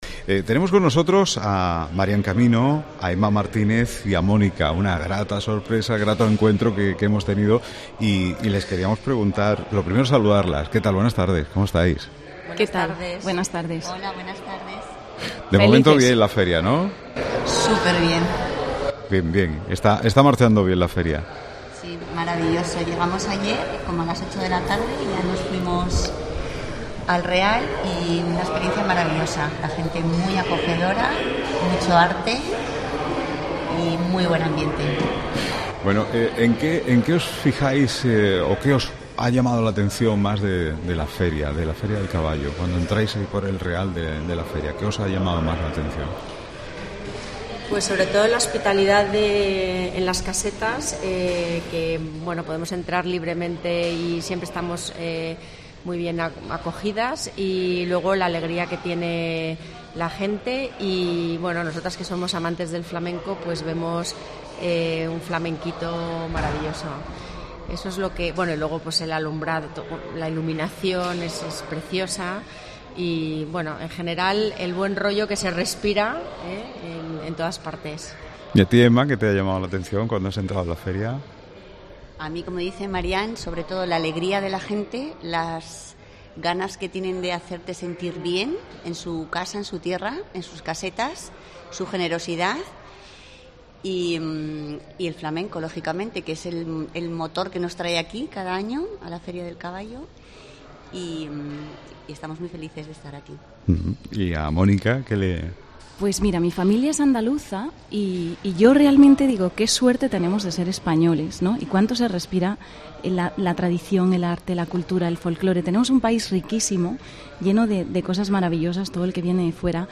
Escucha estos testimonios que desvelan los motivos por los que tantos visitantes eligen Jerez cada mayo